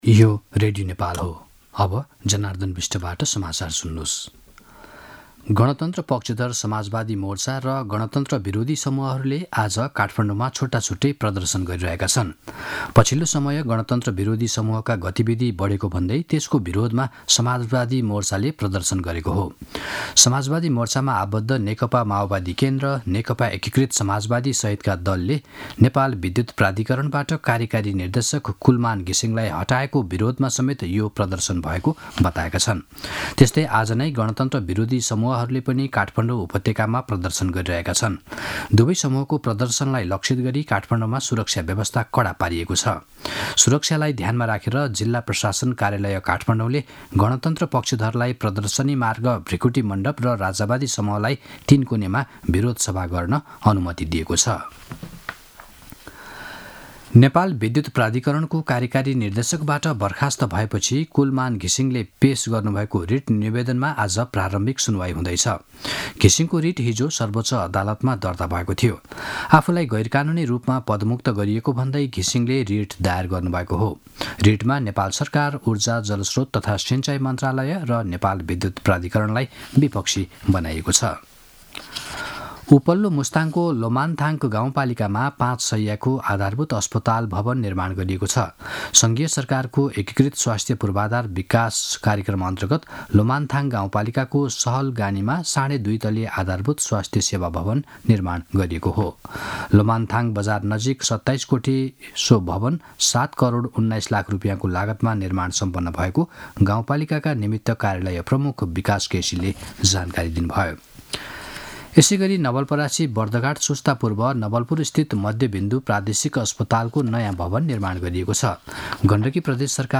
मध्यान्ह १२ बजेको नेपाली समाचार : १५ चैत , २०८१